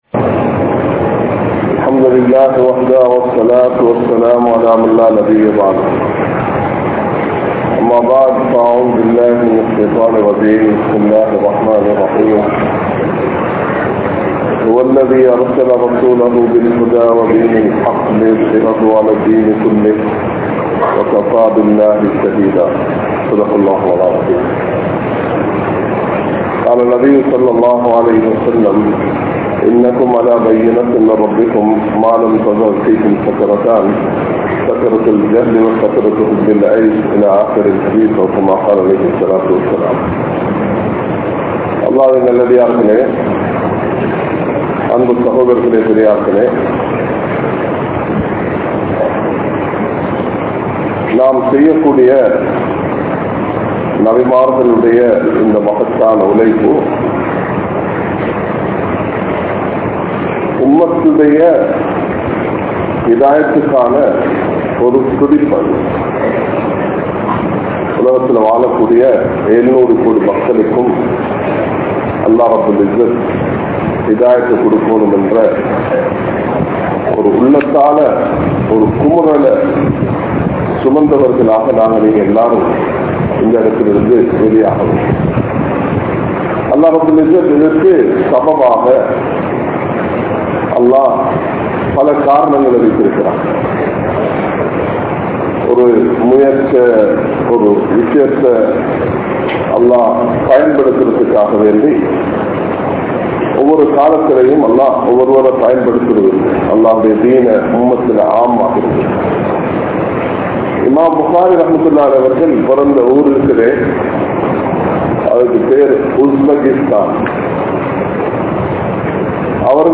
Dhauwaththin Avasiyam (தஃவத்தின் அவசியம்) | Audio Bayans | All Ceylon Muslim Youth Community | Addalaichenai
Colombo, GrandPass Markaz